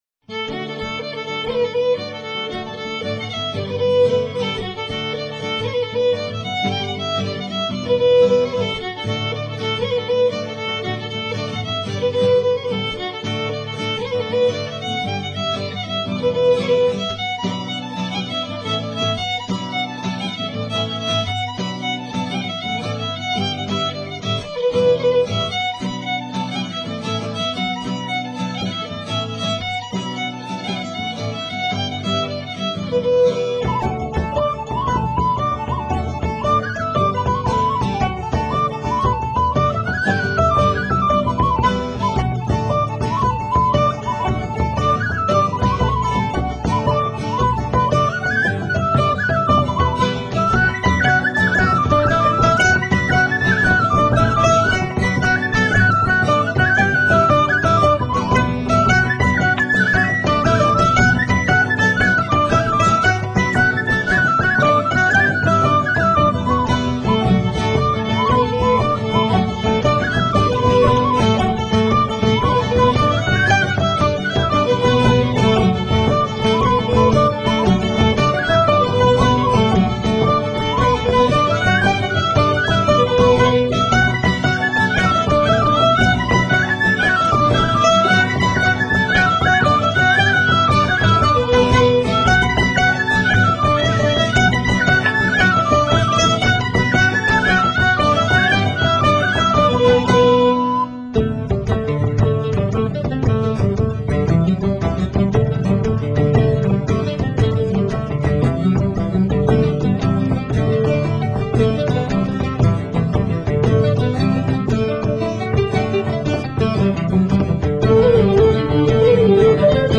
jigs
lead & rhythm guitars, mandolin
violin, tin whistle,Alabaster flute, mandola
bodhrán.